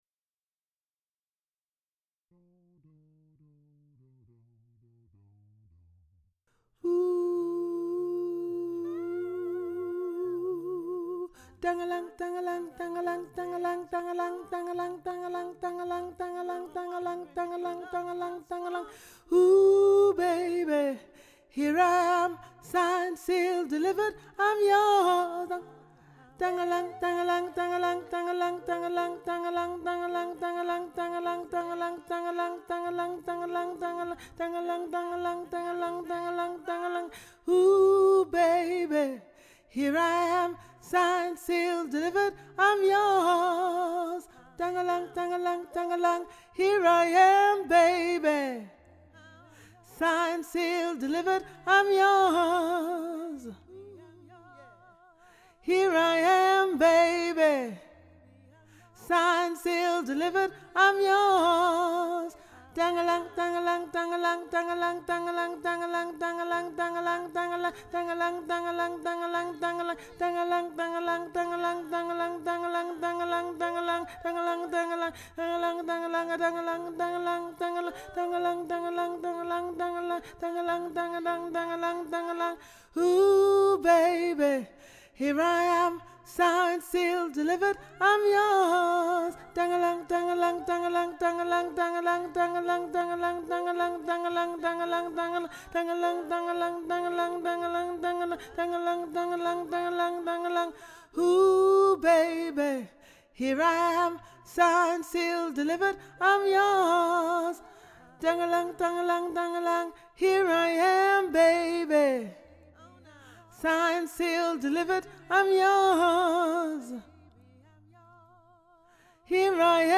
Genre: Choral.